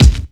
Kicks
DrKick66.wav